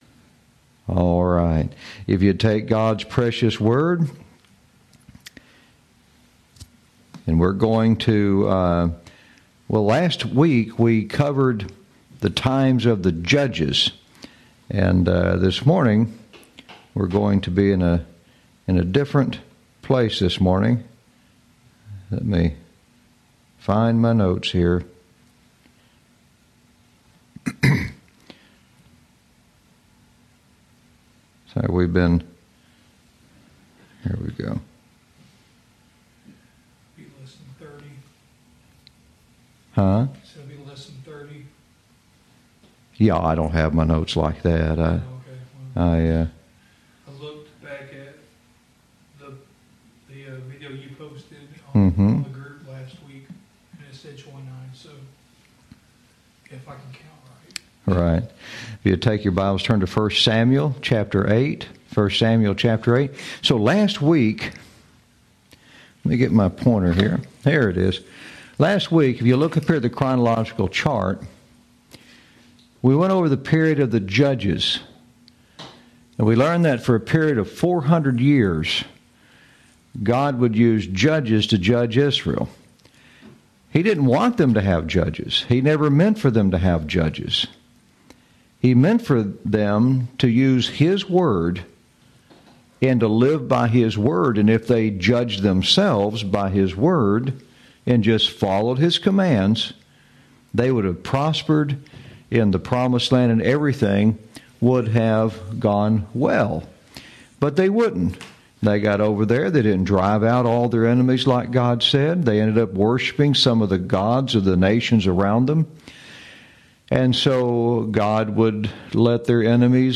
Lesson 30